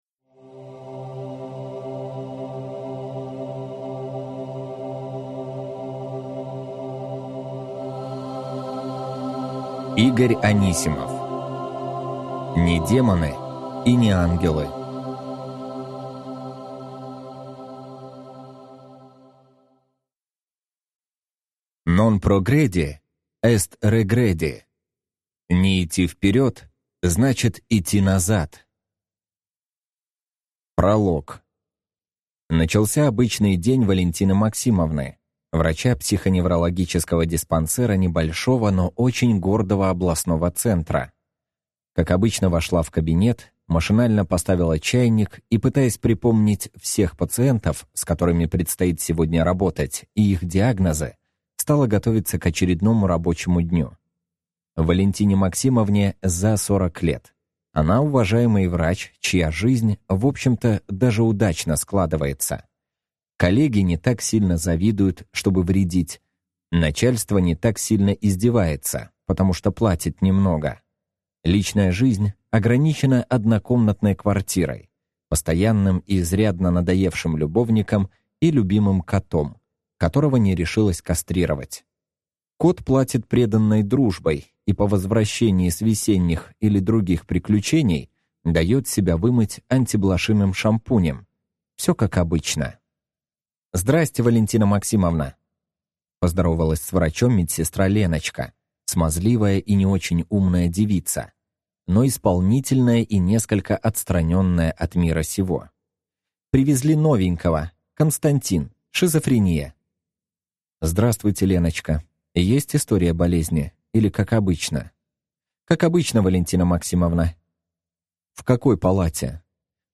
Аудиокнига Не демоны и не ангелы | Библиотека аудиокниг